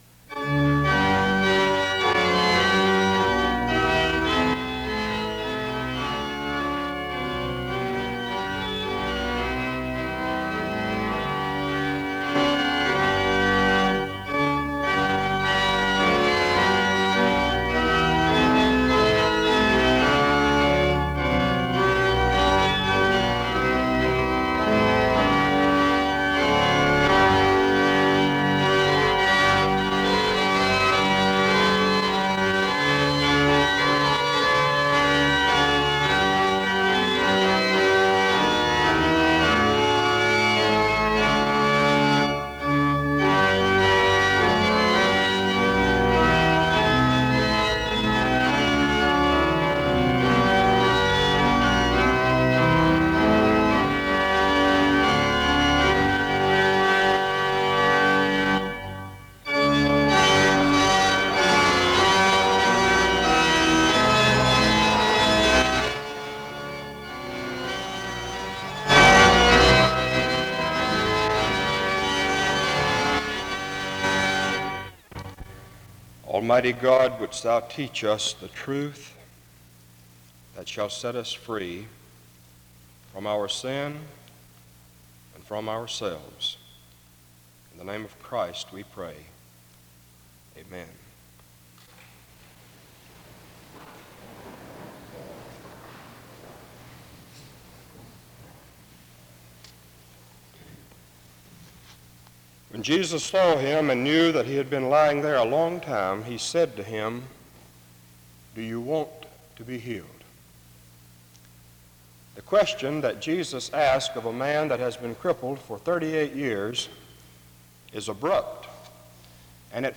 The service begins with music from 0:00-1:14. A prayer is offered from 1:16-1:31.
SEBTS Chapel and Special Event Recordings SEBTS Chapel and Special Event Recordings